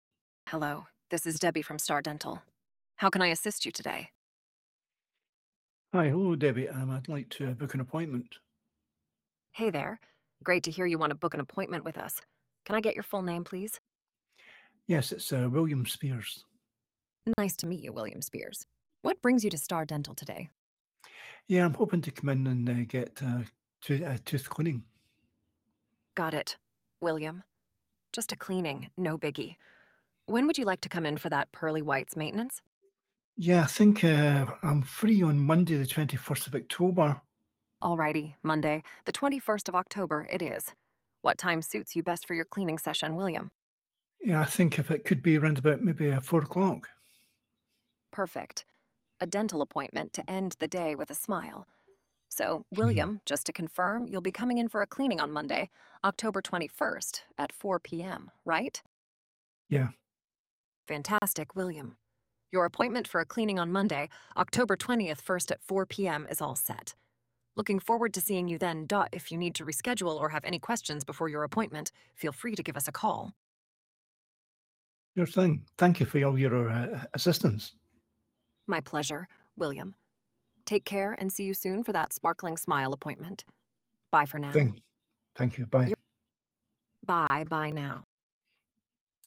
Listen to our Beautiufl AI Dental Agent take call's from customers and making appointments.